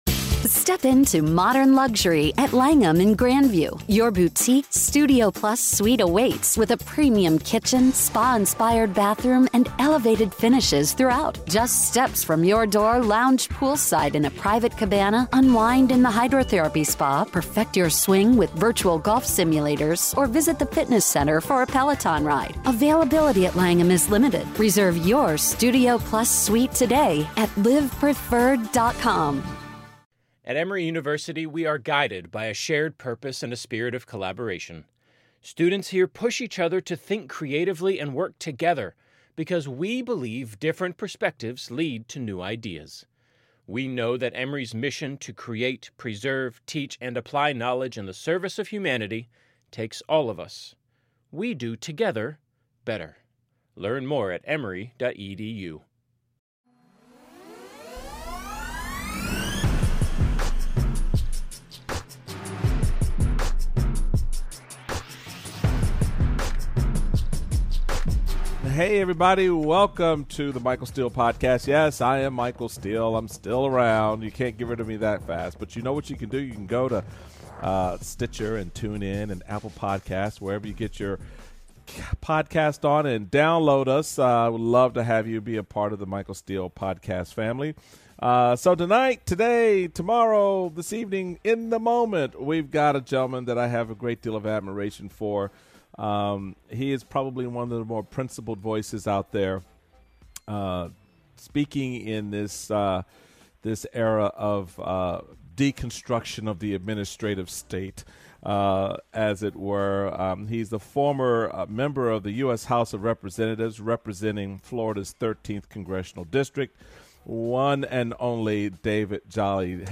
Michael and guest David Jolly discuss Attorney General Bill Barr and his insane performance on Capitol Hill this week. Is it time for impeachment? And is Nancy Pelosi equal to this moment?